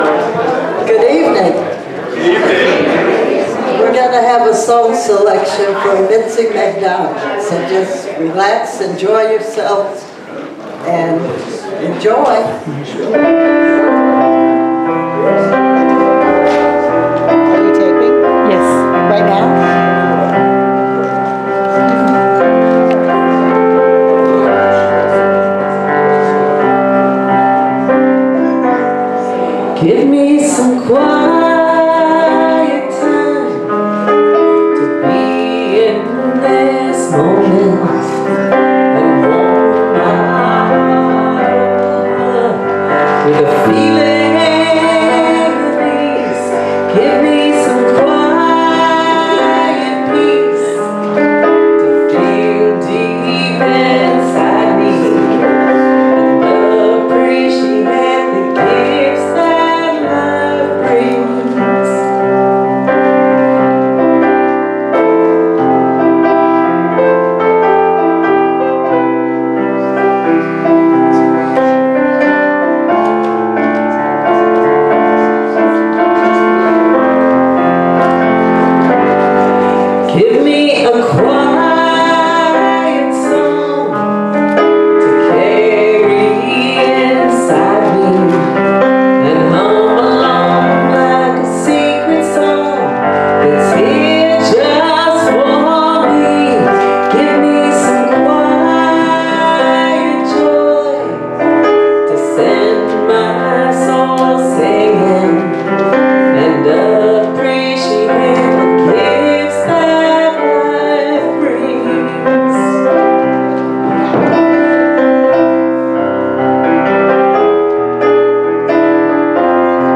Series: Sermons 2024